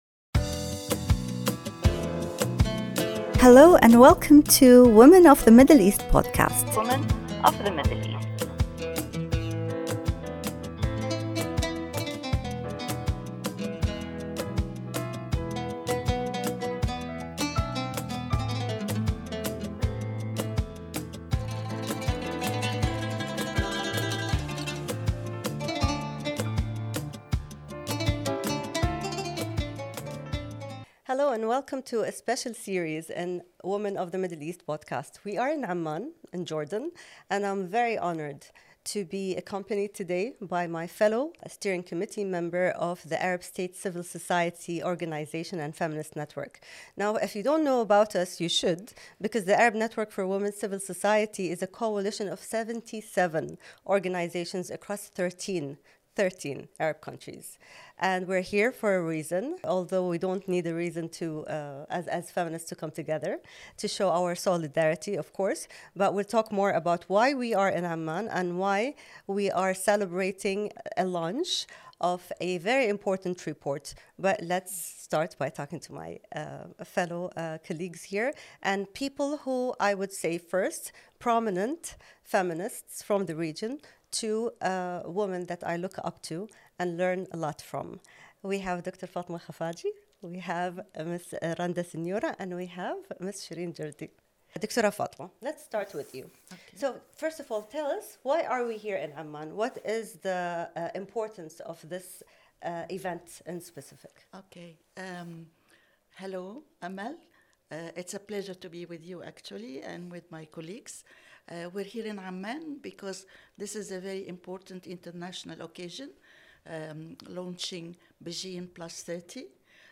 Tune in as Arab States Civil Society Organizations and feminist network members come together to reflect on the launch of the parallel regional report assessing progress on the Beijing Declaration and Platform for Action +30 in the Arab region. In this insightful discussion, we delve into the strides made over the past few decades, the challenges still faced, and the collective vision for a more inclusive and equitable future.